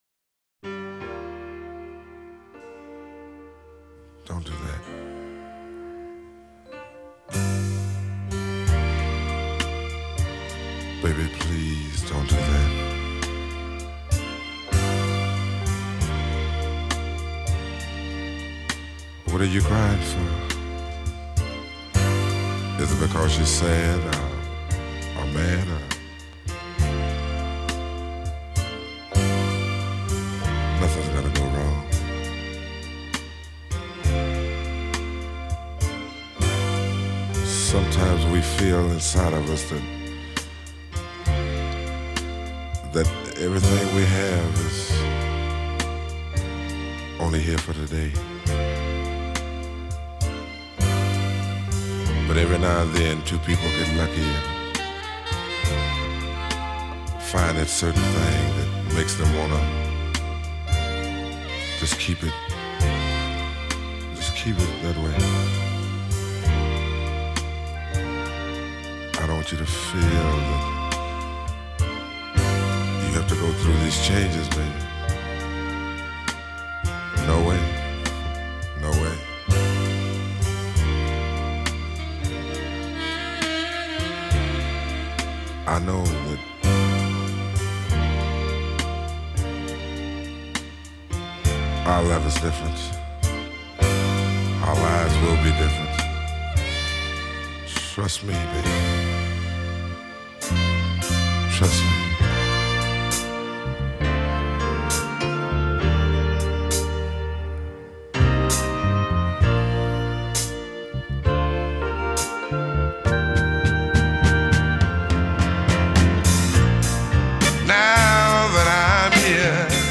глубокий баритон и откровенно соблазнительная лирика.